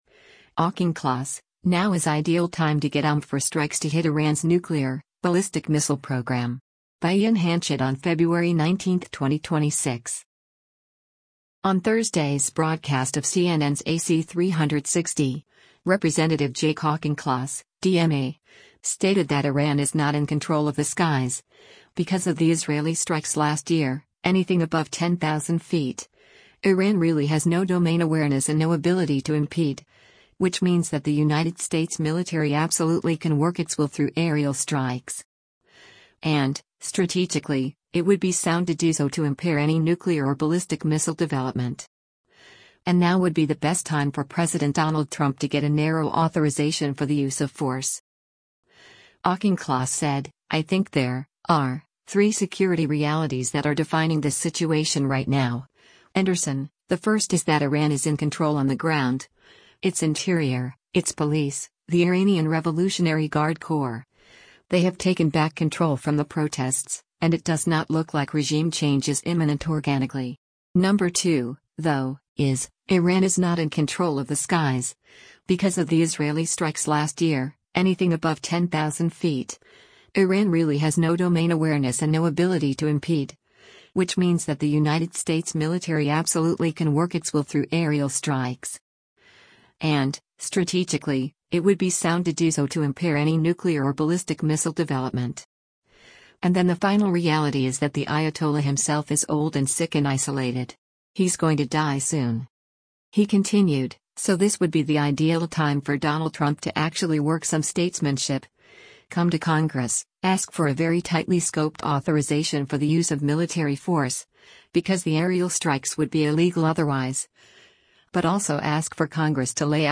On Thursday’s broadcast of CNN’s “AC360,” Rep. Jake Auchincloss (D-MA) stated that “Iran is not in control of the skies, because of the Israeli strikes last year, anything above 10,000 feet, Iran really has no domain awareness and no ability to impede, which means that the United States military absolutely can work its will through aerial strikes.